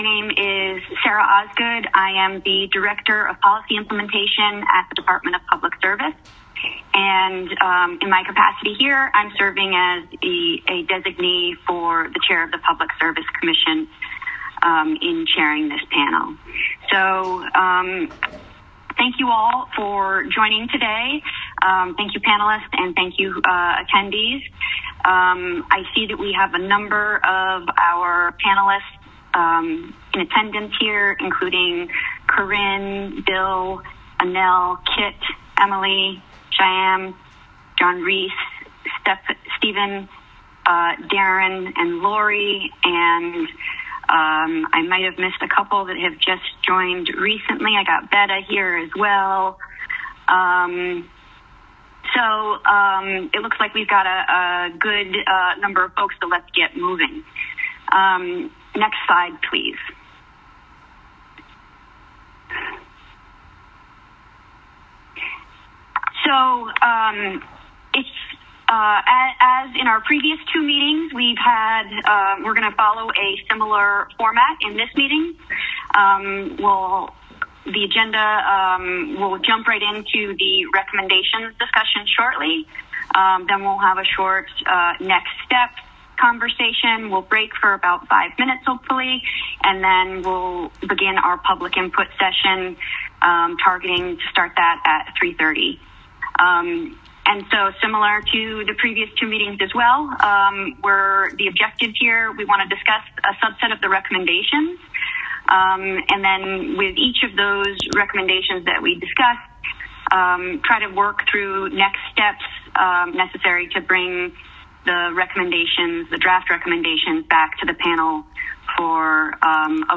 listen to the meeting recording